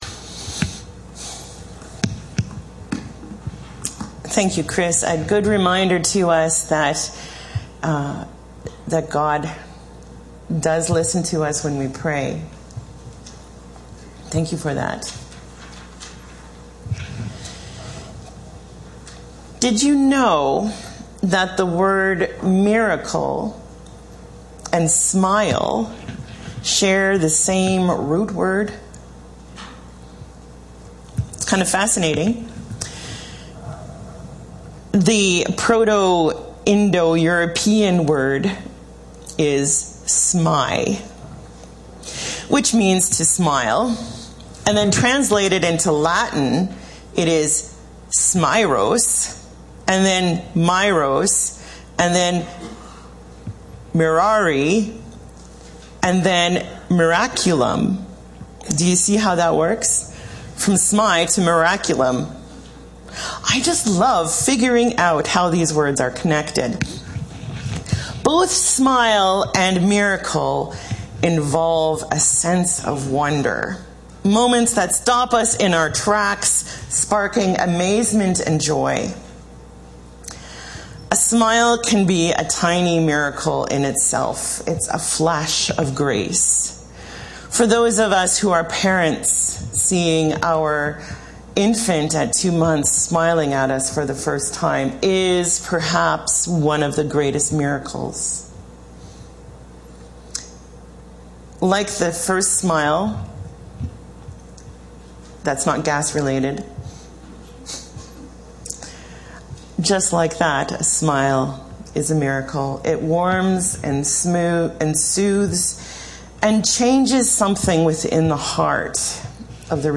Passage: John 2:1-11 Service Type: Sunday Morning